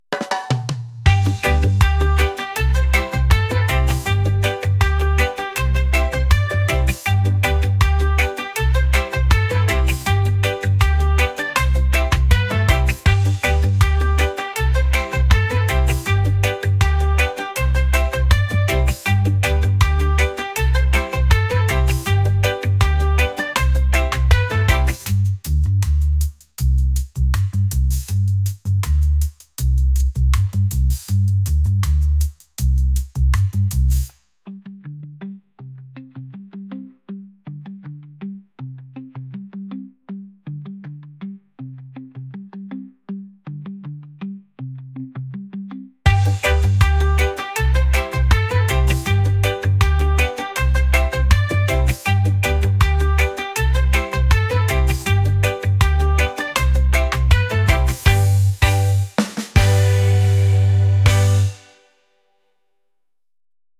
reggae | pop